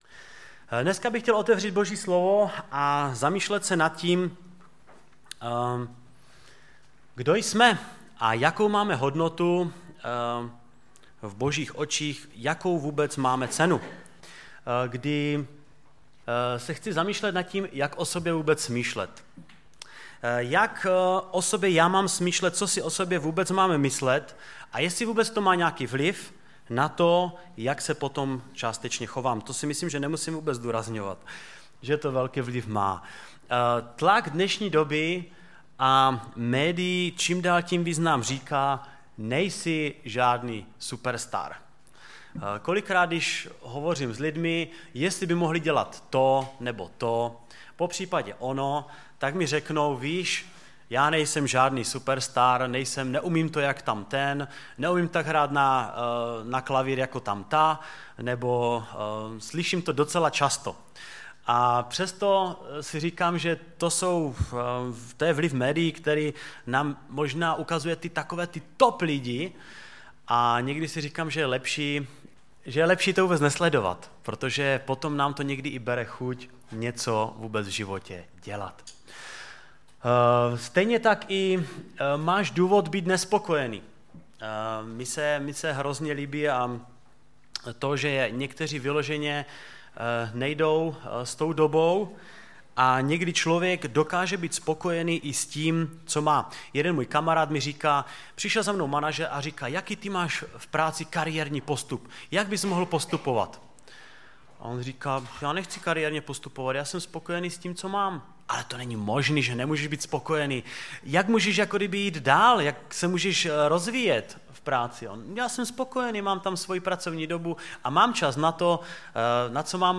Kázání
ve sboře Ostrava-Radvanice v rámci Památky Večeře Páně.